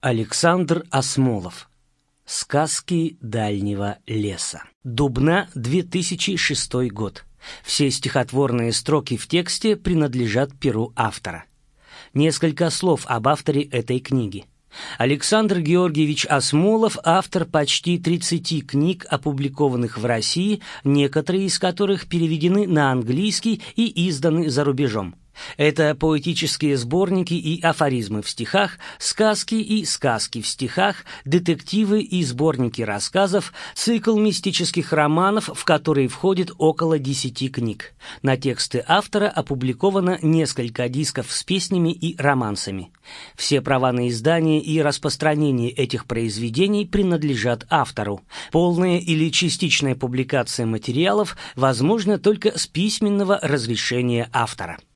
Аудиокнига Сказки Дальнего леса | Библиотека аудиокниг